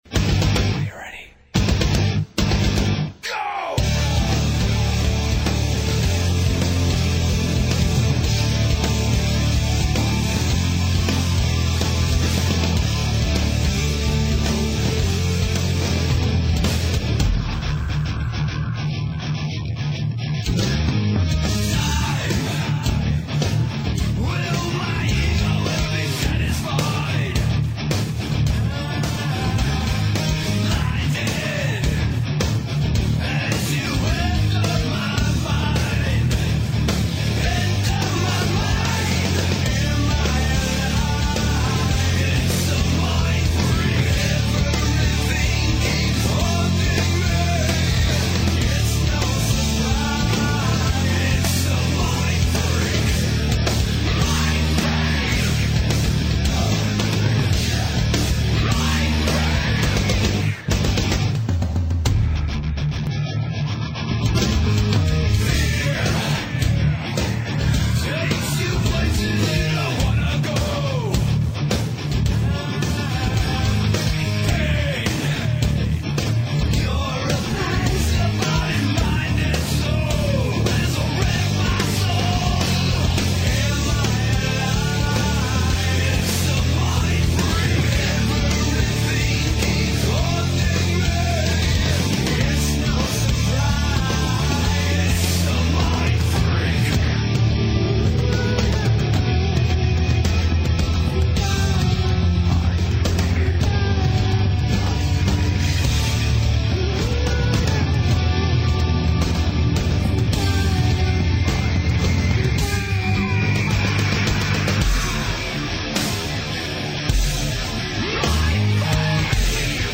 Это толи на диктофон записали, толи еще как...